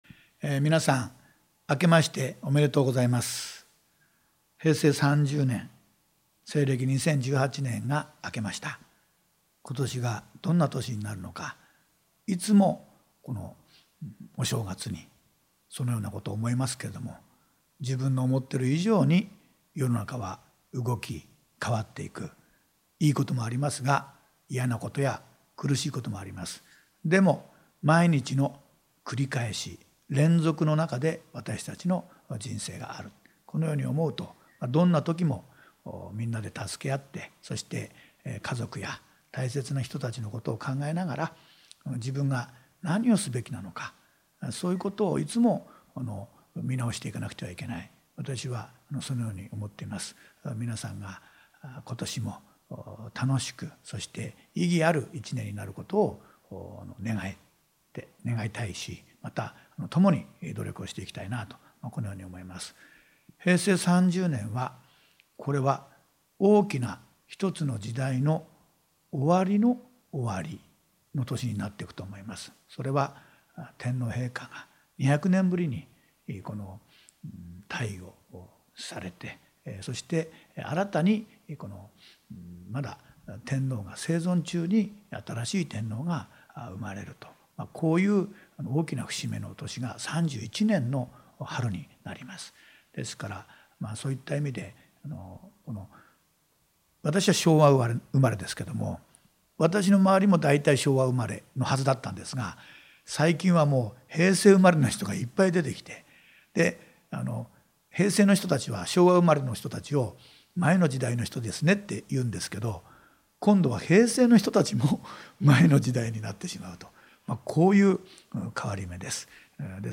1月1日(月)～3日(水)、毎朝8時30分頃～9時、FM川口で、私の新年のお祝いコメントが放送されています。（2018年01月01日）